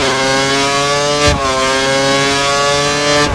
Index of /server/sound/vehicles/lwcars/f1